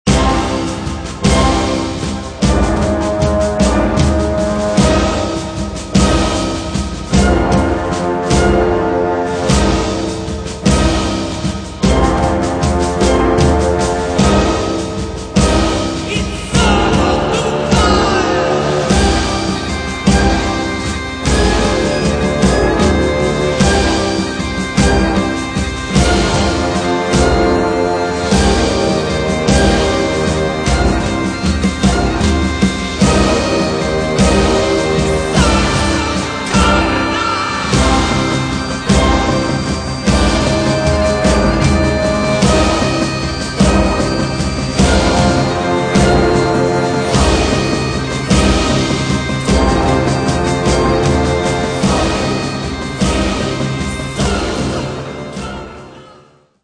Sombres, incantatoires et mécaniques